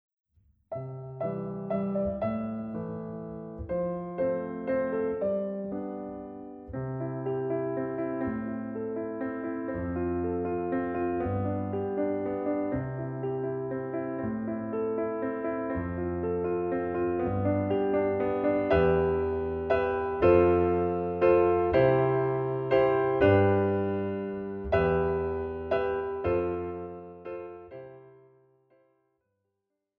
Besetzung: Oboe und Klavier